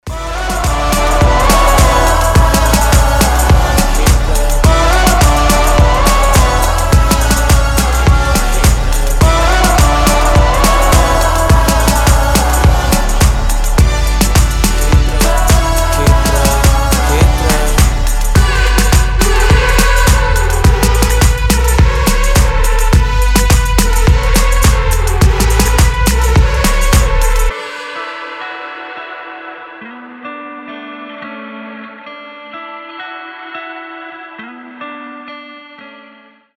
• Качество: 320, Stereo
поп
атмосферные